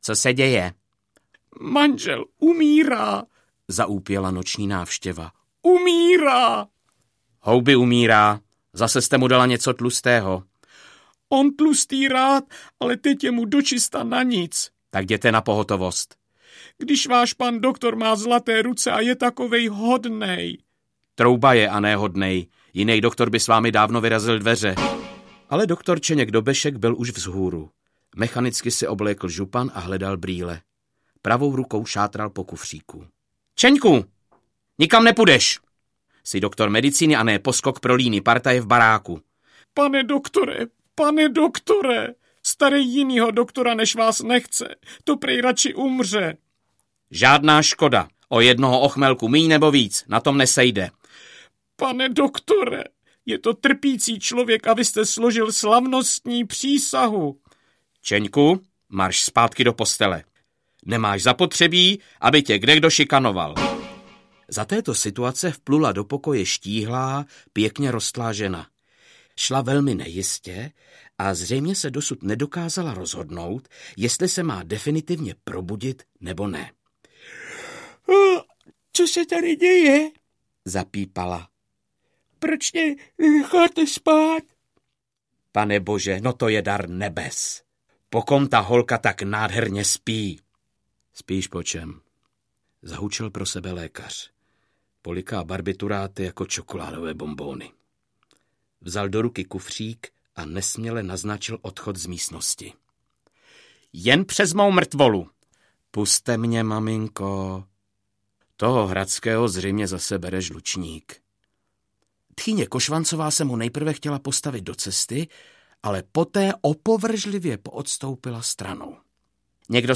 Herec Bob Klepl rozehrává skvělý komediální příběh.
Ukázka z knihy
Jako interpret nahrávky dokazuje, že je mistrem slovní komiky, skvělým imitátorem a poutavým vypravěčem. Pro roli smolařského a svérázného lékaře, který se zaplétá do nečekaných dobrodružství v Africe, ale i do sítě ženských intrik, nebylo lepší volby aBohumil Klepl vdechnul nejen hlavnímu hrdinovi, ale i dalším mužským či ženským postavám originální hlasovou podobu.